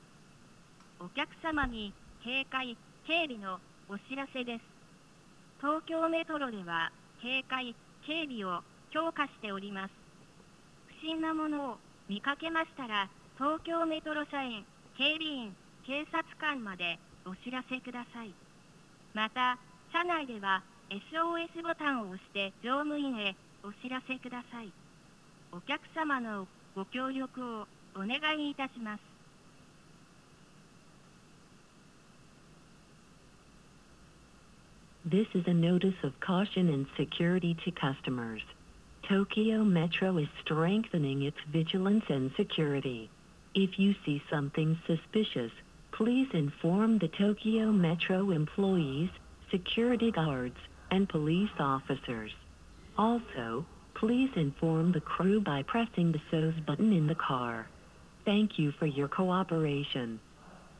スピーカー種類 TOA天井型
警戒・警備啓発放送1
音声は、警戒・警備強化の啓発放送です。10:0022:00まで10分置きに流れ、放送にも被ります。